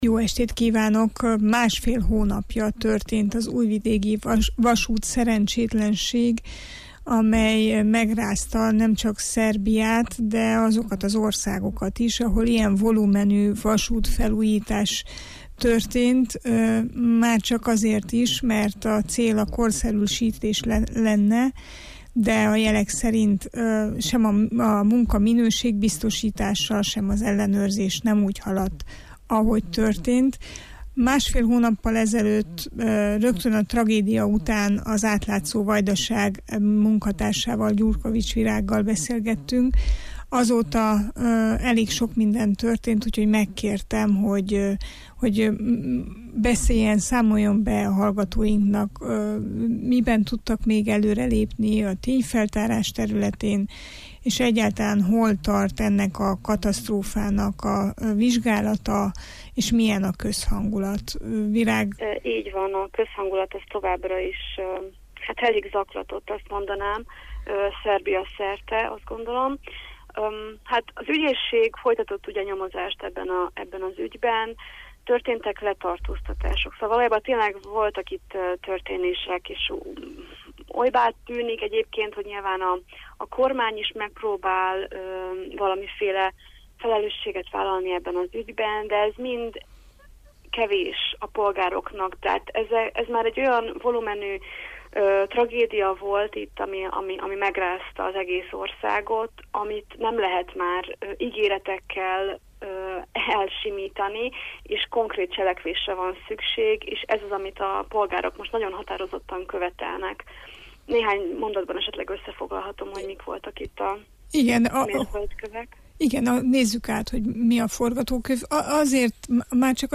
az Értsünk szót adásában beszélgettünk